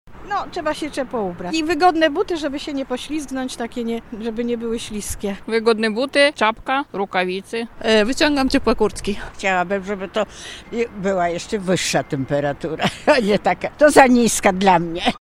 Warunki pogodowe sprawdzaliśmy na zewnątrz z mikrofonem.
Zima-sonda-1z2.mp3